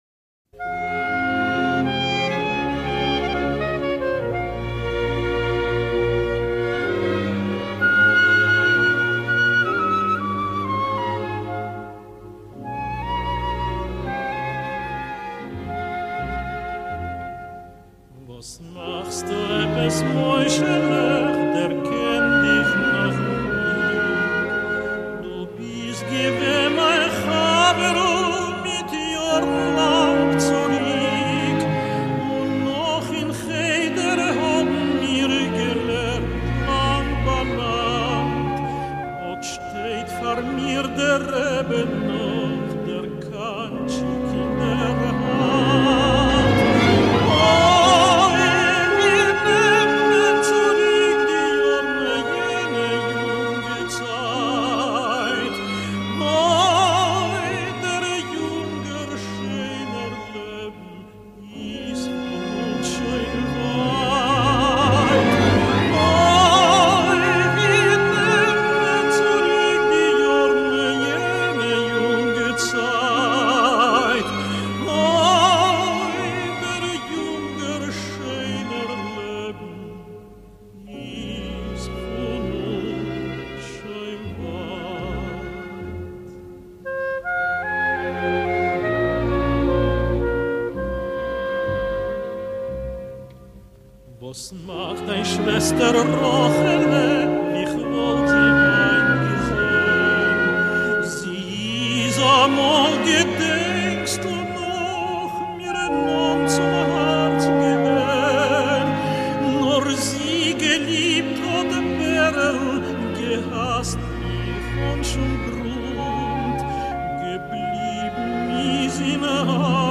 מנגינה מאד מרגשת (אמנם לא עצובה ונוגה, אבל כן מרטיטה)